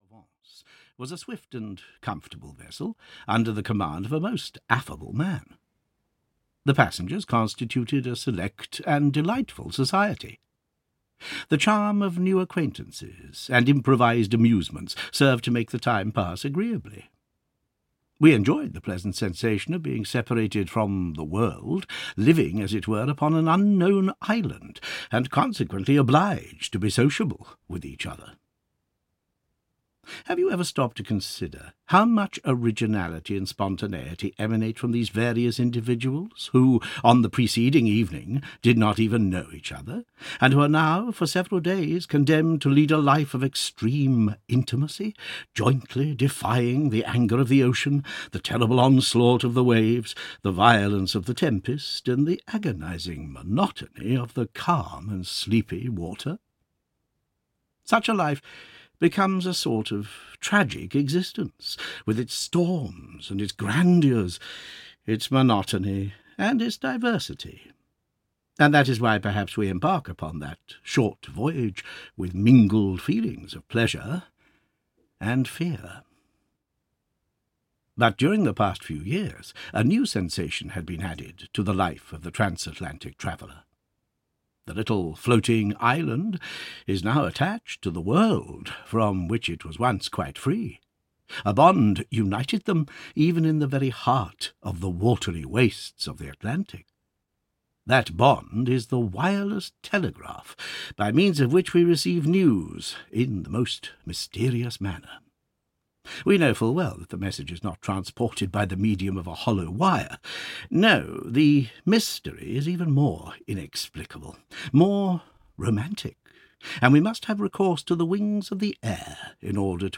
Audio knihaArsène Lupin, Gentleman-Burglar (EN)
Ukázka z knihy